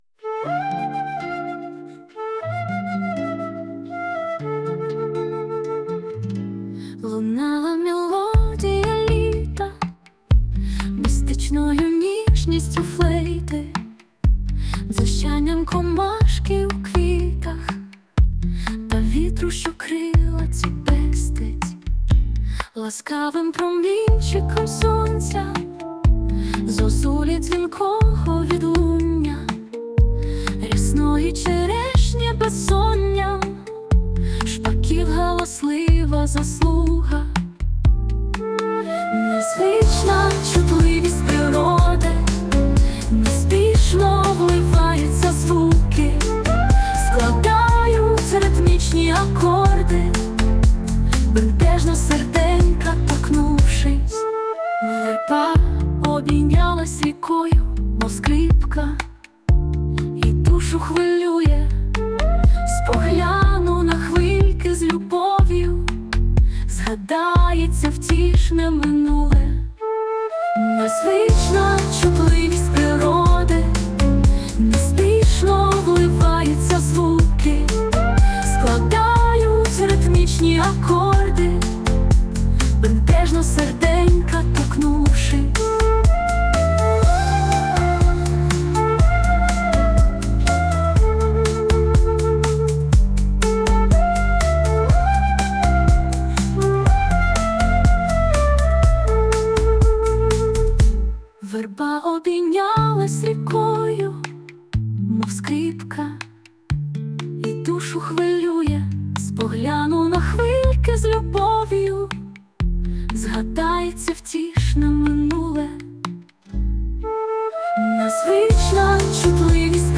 Музична композиція створена за допомогою SUNO AI
Дуже гарно, по-літньому співуче і яскраво! 16